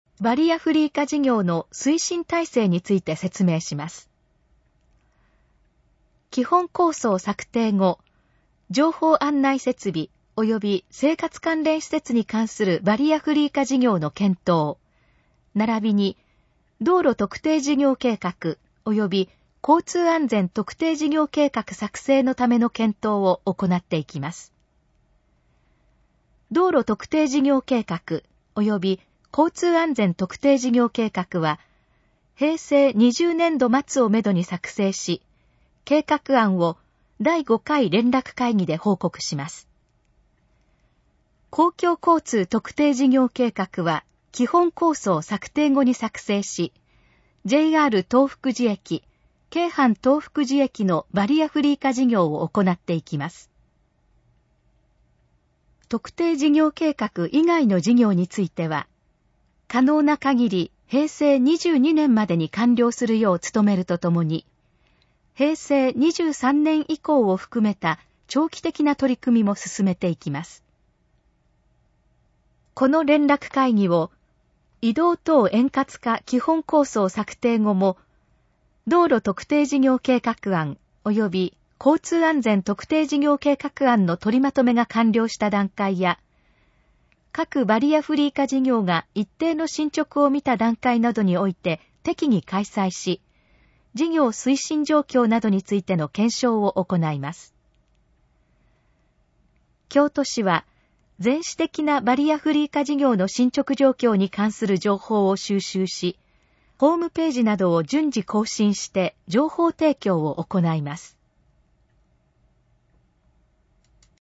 このページの要約を音声で読み上げます。
ナレーション再生 約447KB